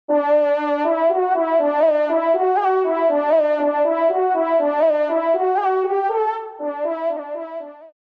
FANFARE
Extrait de l’audio-pédagogique (Tester)
Pupitre de Chant